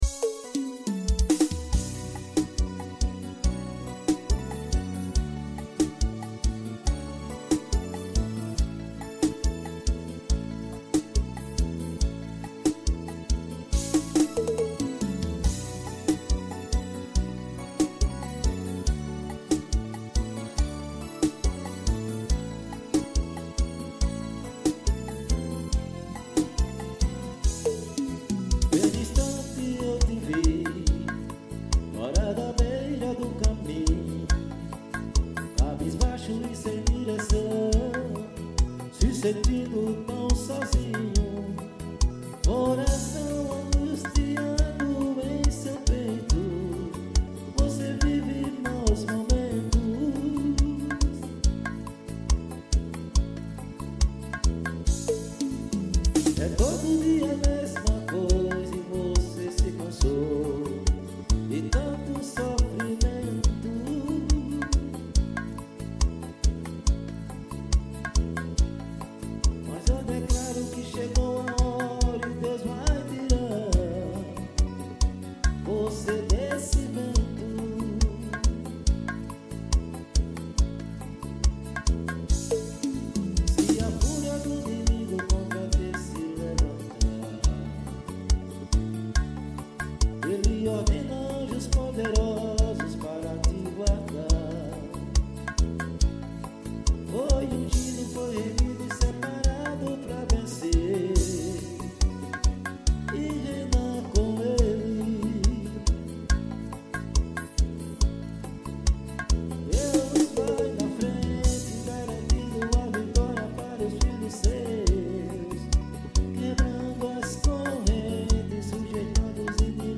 AO VIVO SHOW.